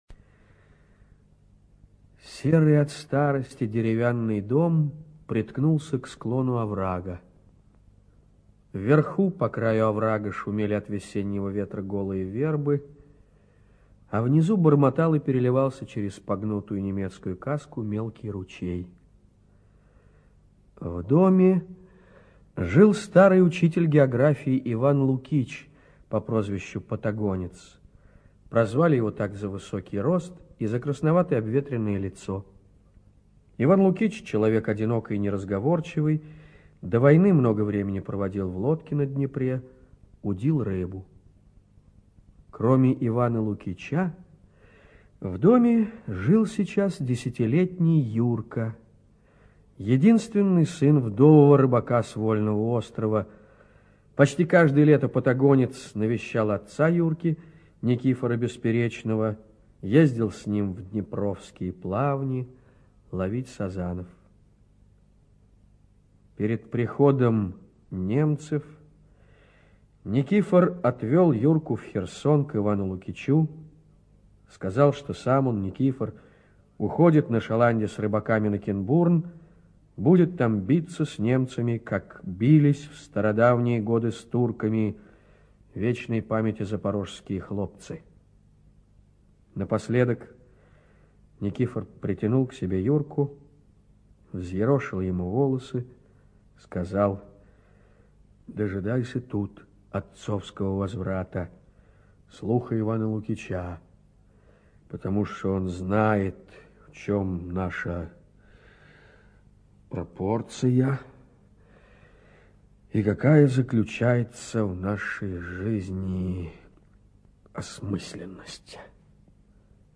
ЧитаетТабаков О.